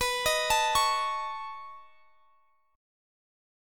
Listen to Cb9 strummed